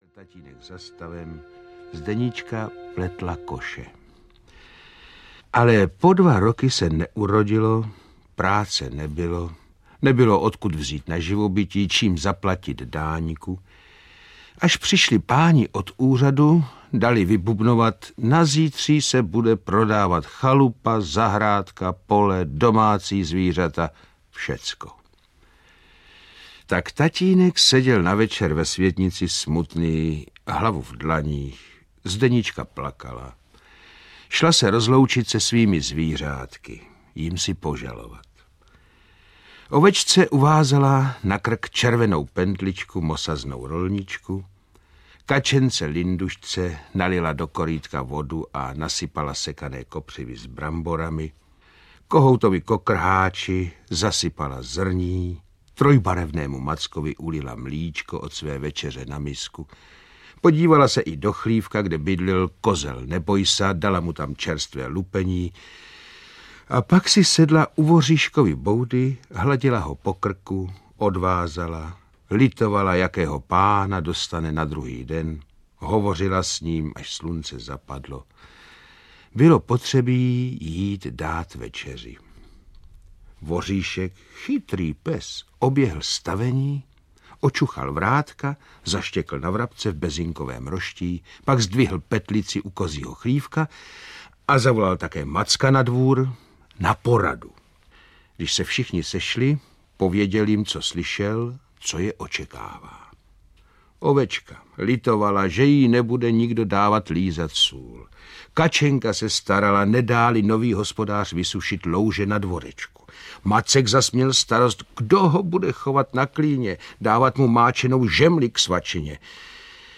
Ukázka z knihy
• InterpretPetr Haničinec, Růžena Merunková, Jaroslav Moučka, Antonie Hegerlíková, Luděk Munzar